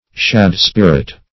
Shad-spirit \Shad"-spir`it\, n.